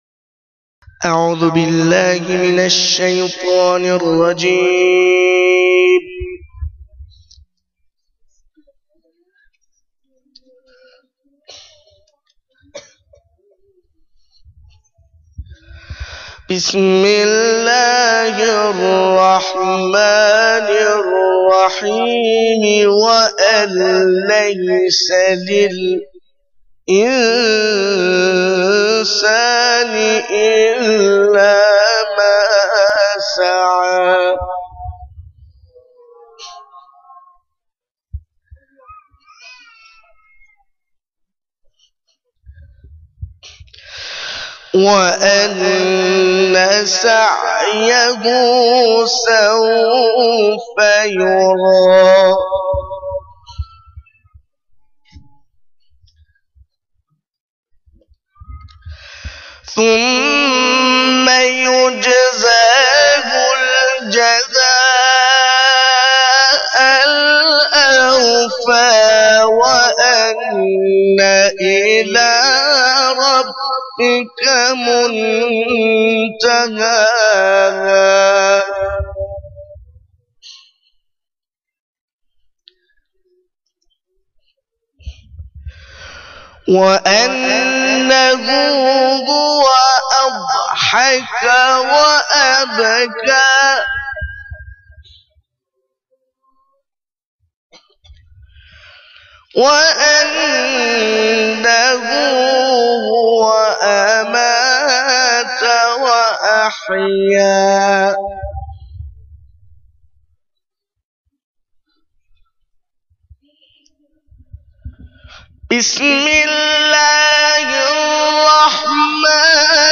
سخنرانی10.1.wma